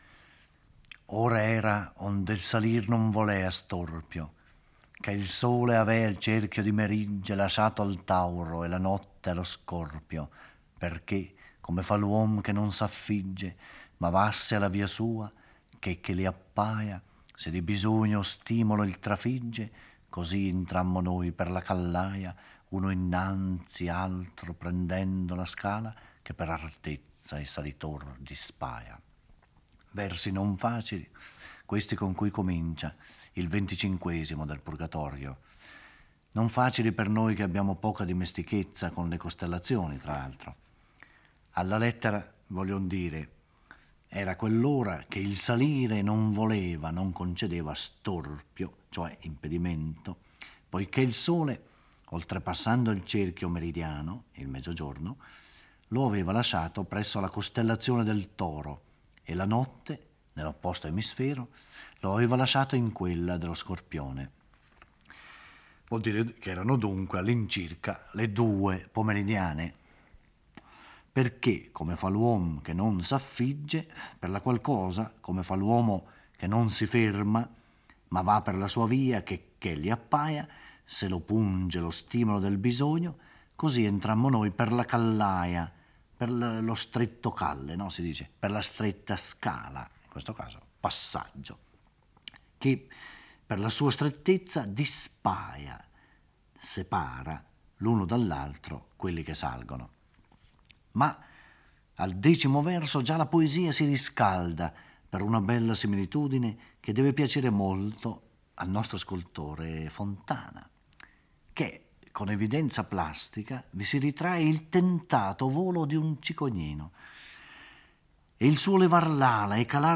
Giorgio Orelli legge e commenta il XXV canto del Purgatorio.